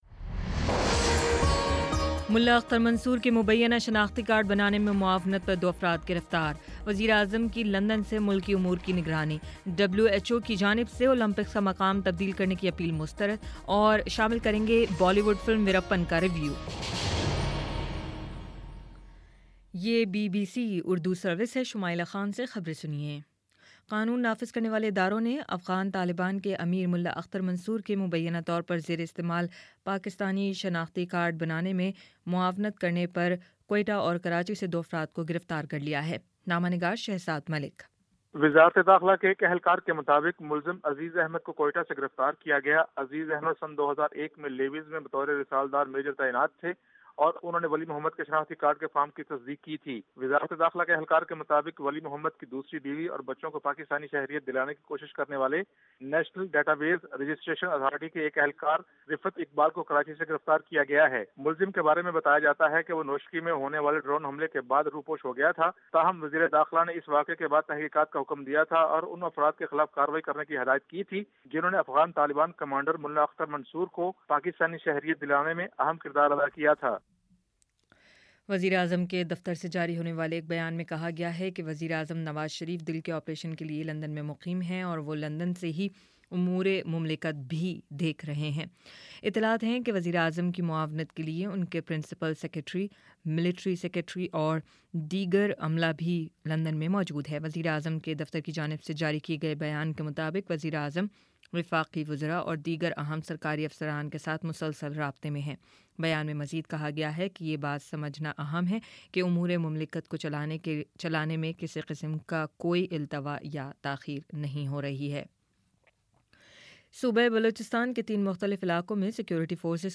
مئی 28 : شام چھ بجے کا نیوز بُلیٹن